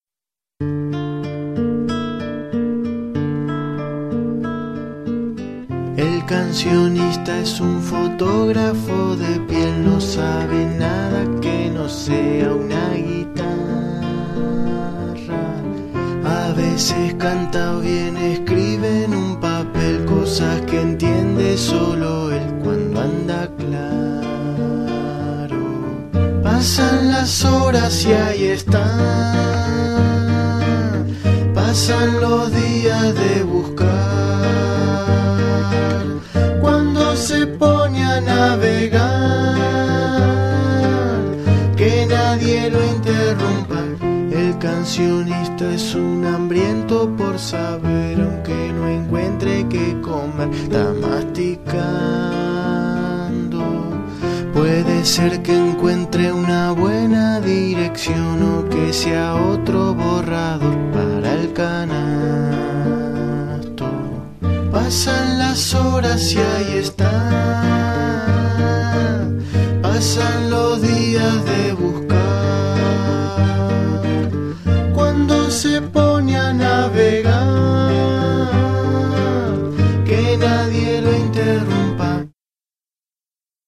Comparto algunos registros de grabaciones de estudio y caseras.
piano